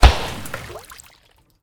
waterballoon
balloon pop splash water water-balloon sound effect free sound royalty free Memes